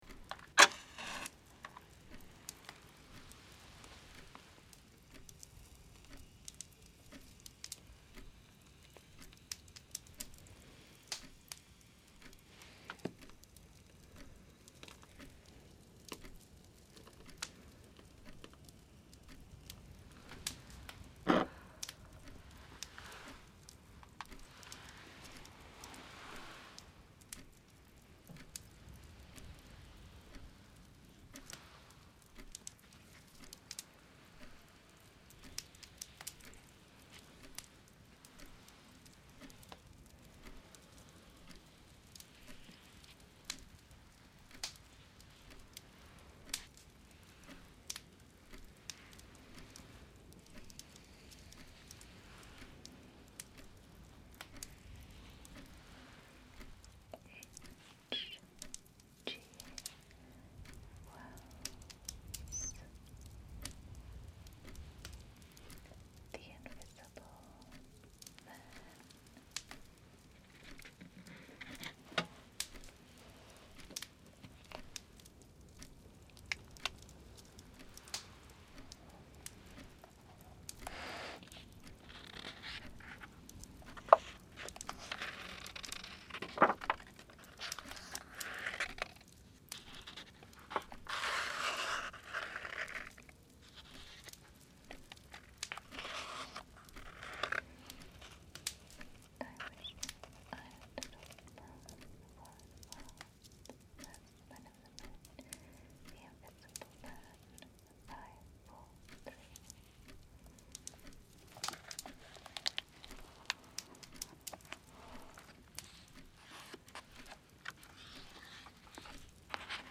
The Invisible Man - Unintelligible/Inaudible Whispered Reading WITH ambient sounds